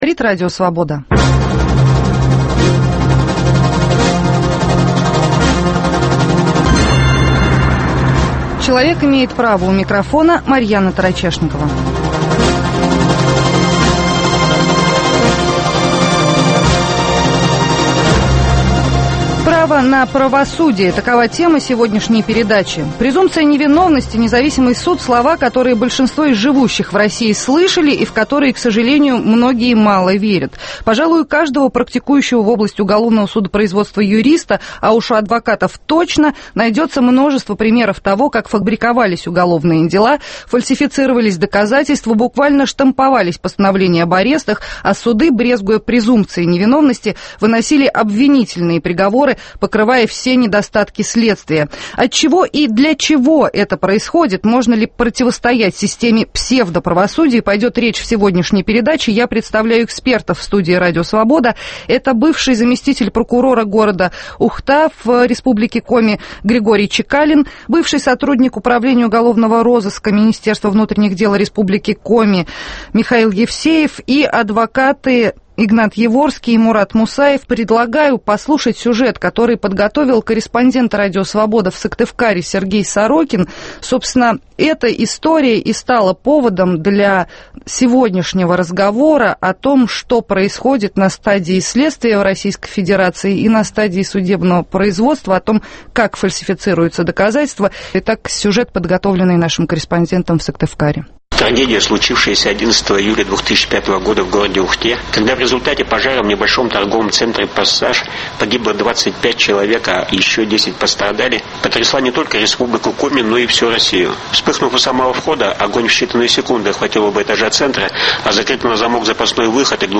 Тему "Право на правосудие" обсуждают в студии Радио Свобода